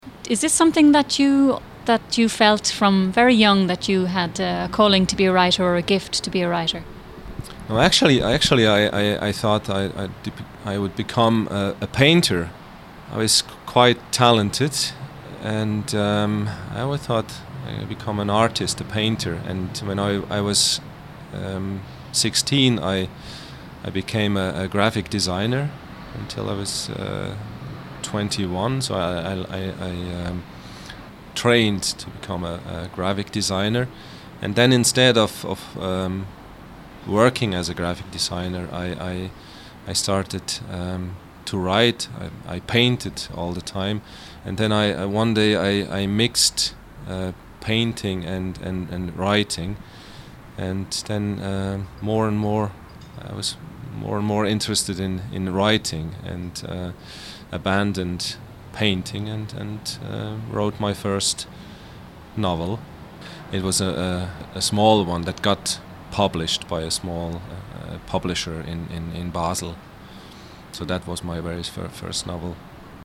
The writer talks about his early creative years.